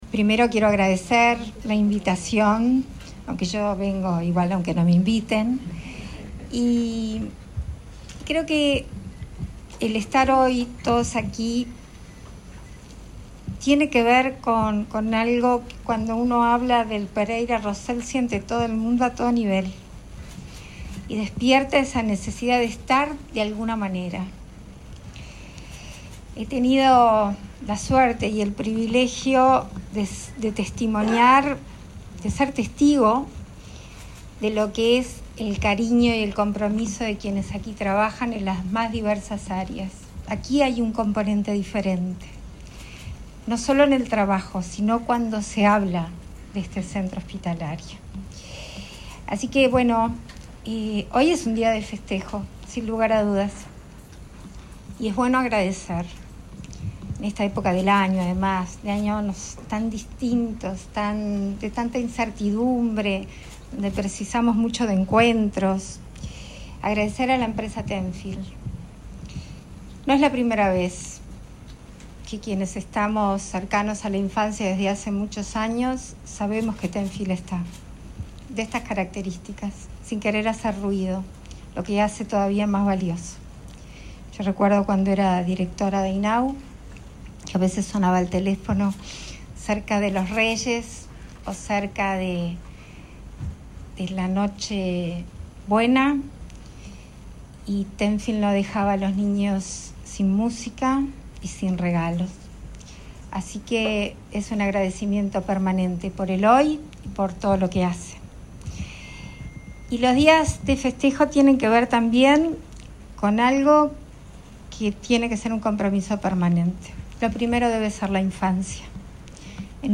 Palabras de la presidenta en ejercicio, Beatriz Argimón
La presidenta en ejercicio, Beatriz Argimón, participó este martes 14 en la presentación de equipamiento de última generación para el hospital Pereira